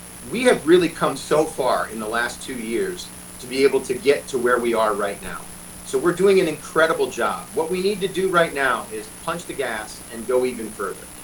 On Tuesday, the Dubuque Area Chamber of Commerce and Greater Dubuque Development Corporation hosted a virtual town hall to provide updates about Dubuque’s air service.  Mayor Brad Cavanagh talked about the effort to bring air service to Dubuque and that the momentum needs to continue.